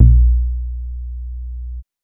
JAH LION BAS.wav